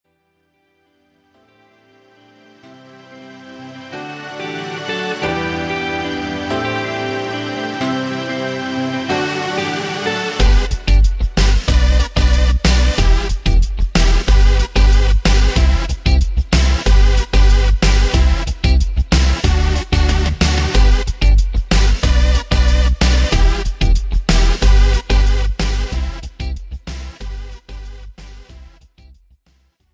Pop
BV Yes